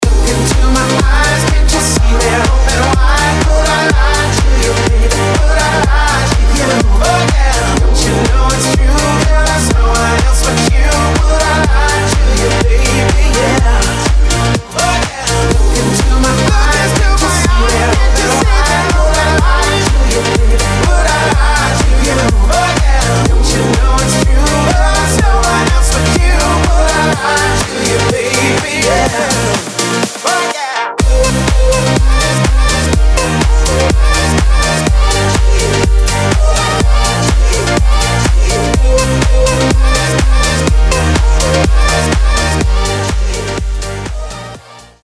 • Качество: 320, Stereo
мужской вокал
vocal
Стиль: House